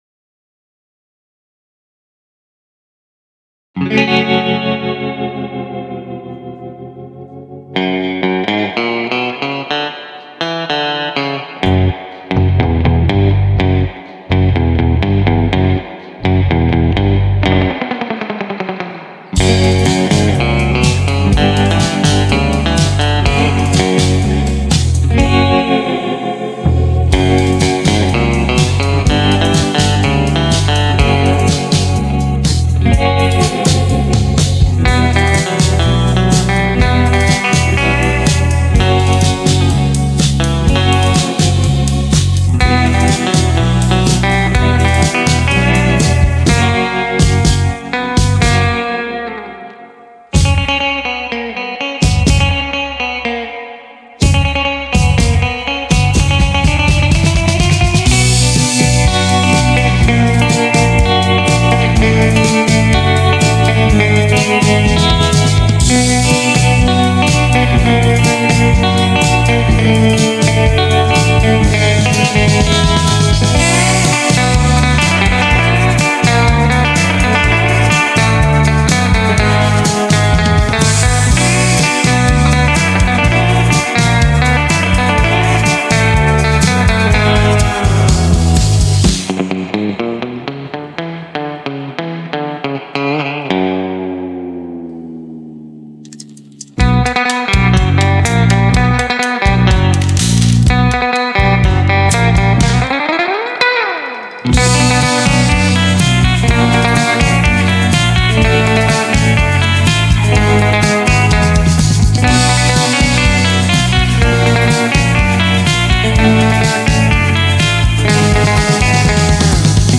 I did all the guitar parts using Mixcraft 9 and My Kemper Rack unit
Mystic Teal Jazzmaster - Doing all the guitar work